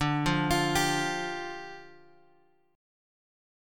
Dmbb5 chord {x 5 3 x 6 3} chord